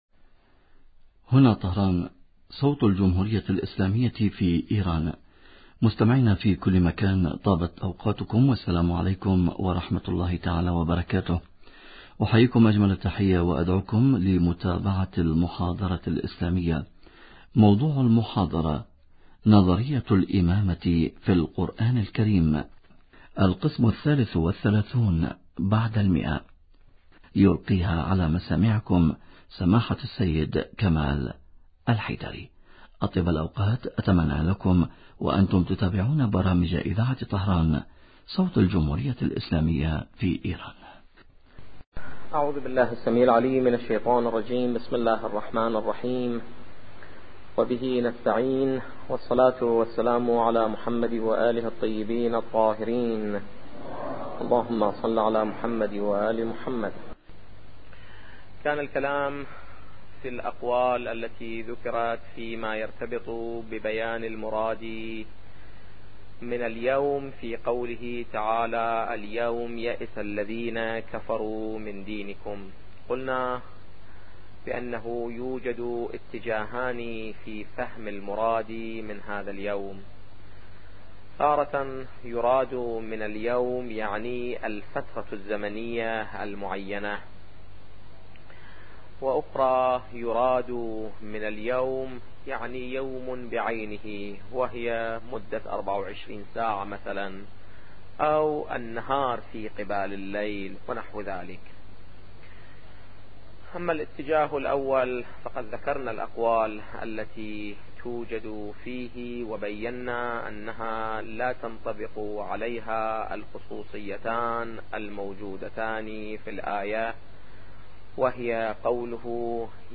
نظرية الامامة في القران الكريم - الدرس الثالث والثلاثون بعد المئة